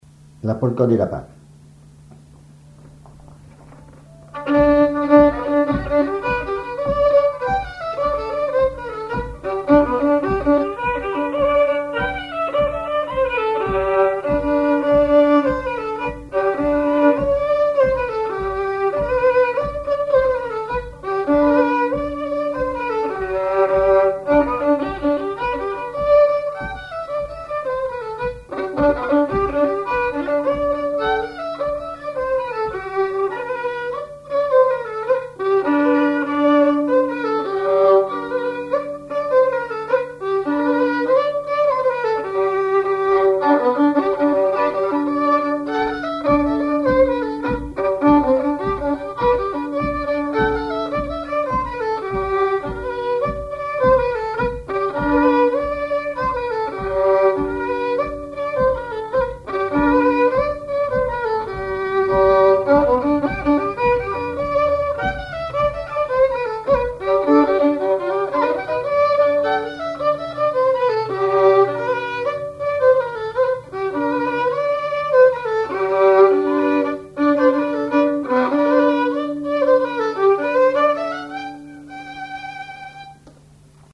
violoneux, violon
polka piquée
instrumentaux au violon mélange de traditionnel et de variété
Pièce musicale inédite